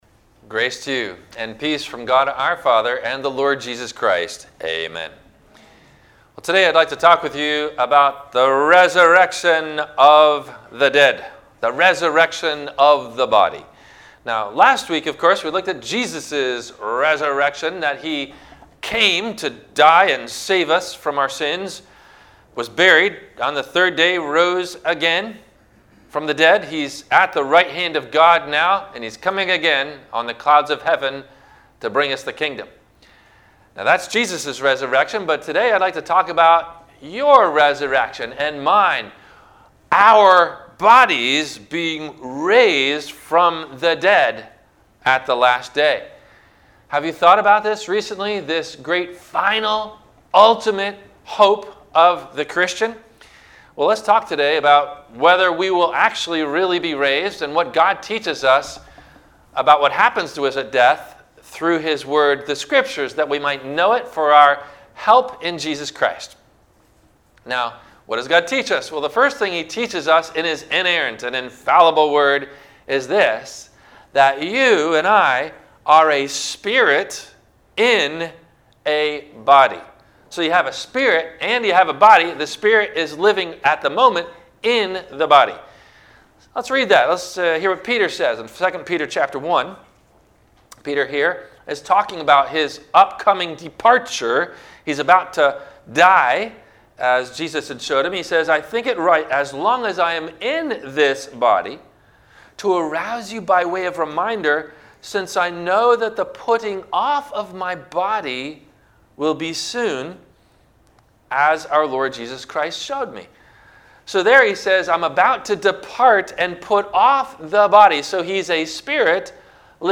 The Ultimate Hope of The Christian - Sermon - April 11 2021 - Christ Lutheran Cape Canaveral